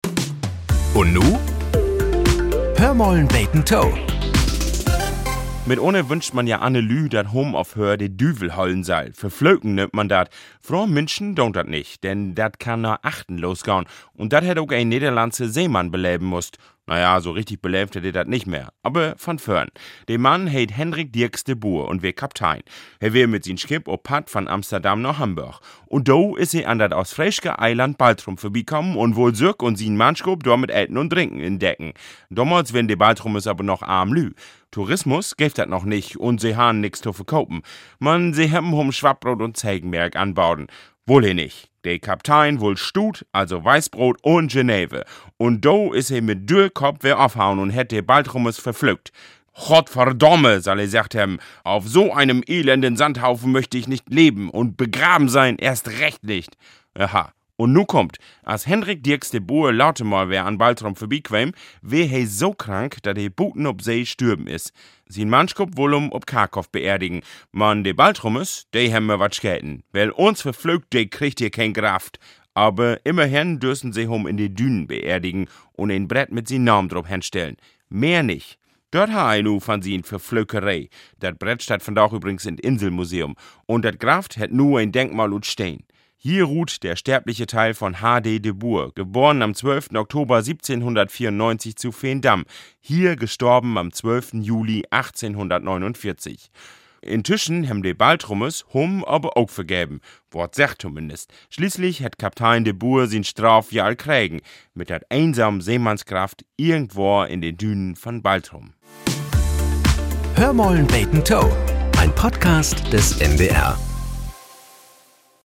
Nachrichten - 14.05.2025